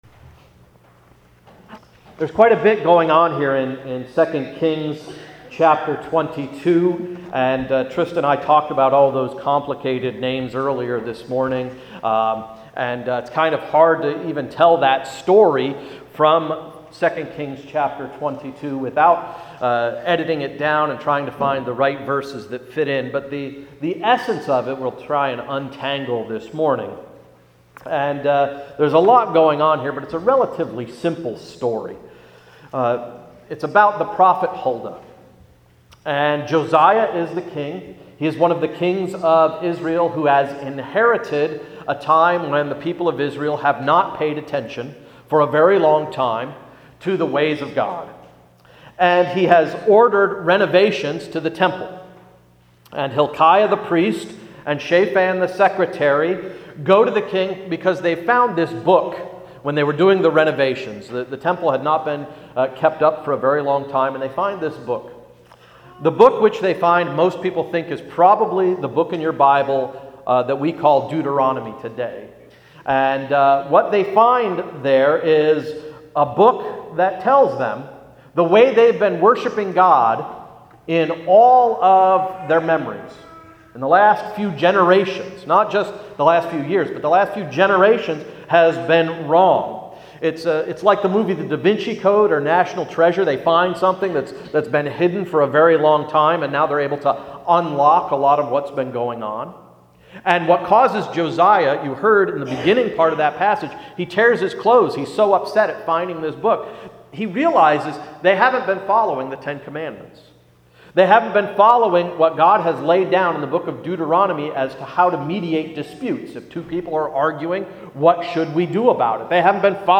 Sermon of March 25–“Worship Wonders”